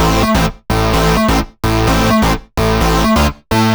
VEE Melody Kits 26 128 BPM Root A.wav